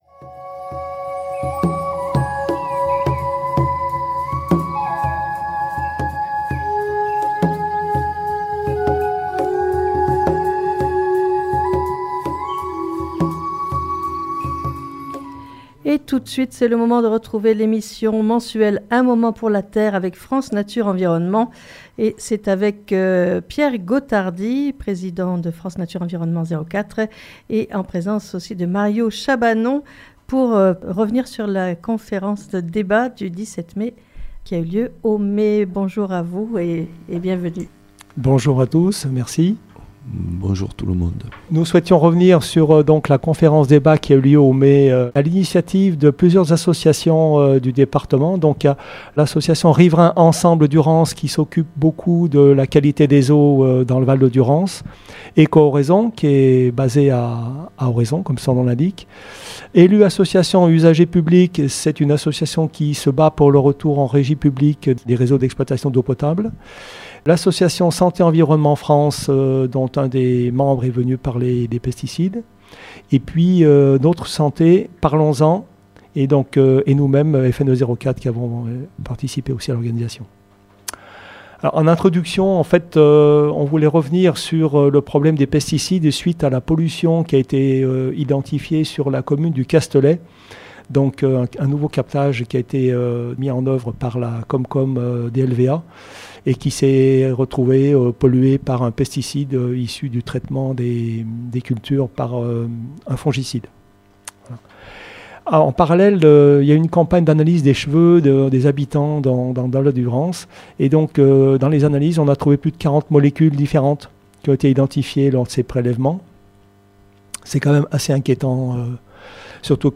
Un moment pour la Terre est une émission mensuelle chaque dernier mercredi du mois, de 9h15 à 10h sur Fréquence Mistral Digne